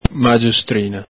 Alto Vic.